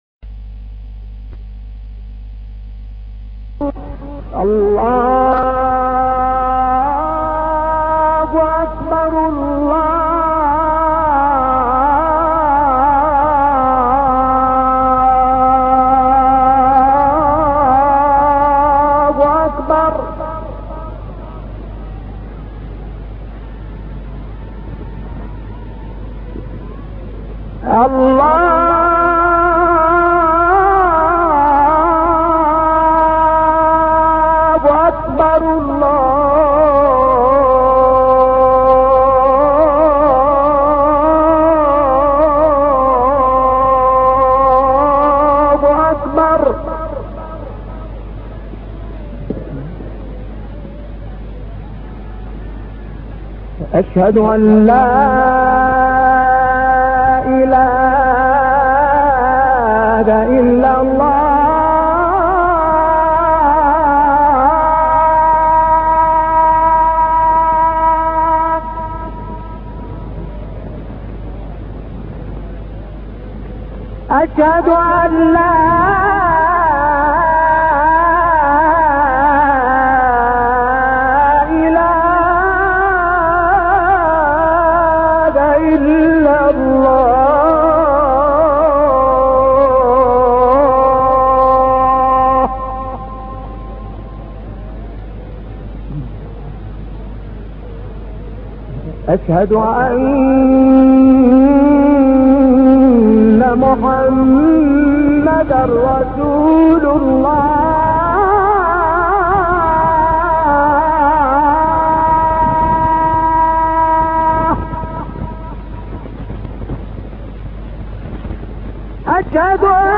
گروه فعالیت‌های قرآنی: گلبانگ اذان، با صدای دلنشین 9 قاری بین‌المللی را می‌شنوید.
اذان شعبان صیاد/ این اذان در مقام سه گاه اجرا شده است.